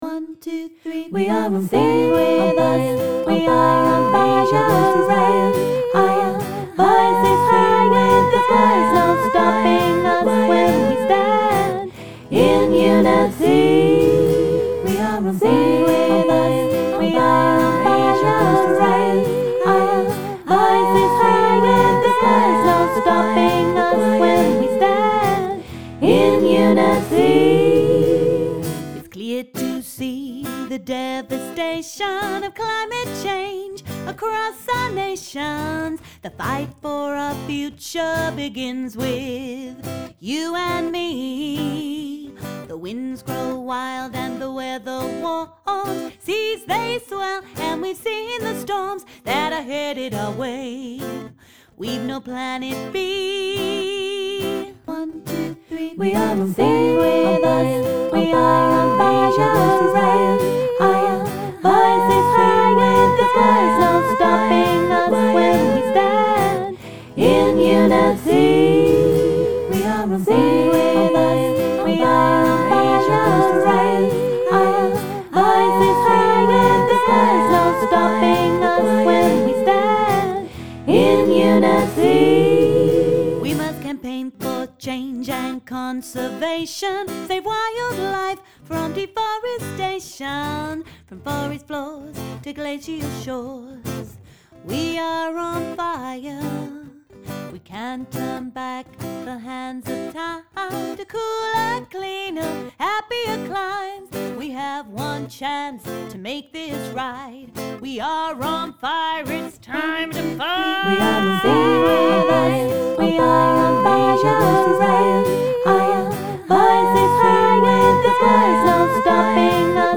St James's Hall on Northumbria University campus hosted the post-March Climate Stalls Festival for the second year.
Uplifting climate singalongs
talented singer/songwriter